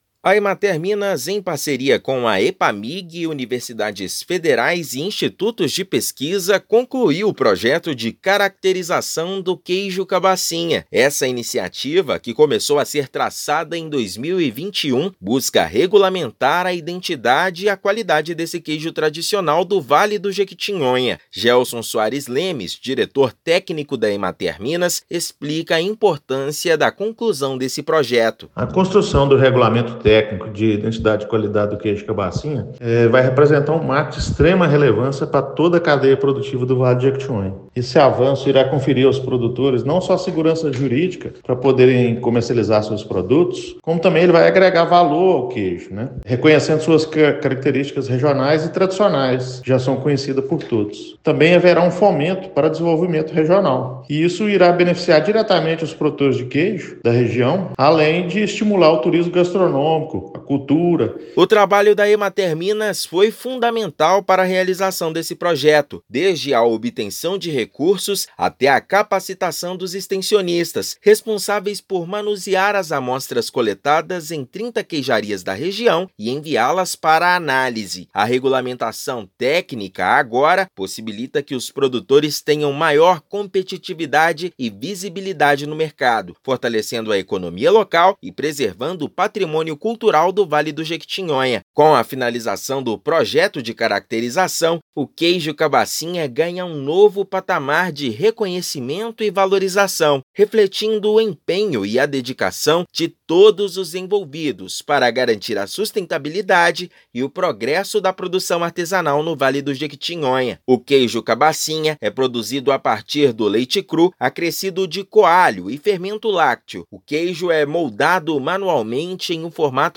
A conquista dá segurança jurídica aos produtores, agrega valor ao queijo e fomenta o desenvolvimento regional. Ouça matéria de rádio.